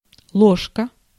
Ääntäminen
Ääntäminen Tuntematon aksentti: IPA: /kɥi.jɛʁ/ Haettu sana löytyi näillä lähdekielillä: ranska Käännös Ääninäyte Substantiivit 1. ложка {f} (ložka) Muut/tuntemattomat 2. ло́жка {f} (lóžka) Suku: f .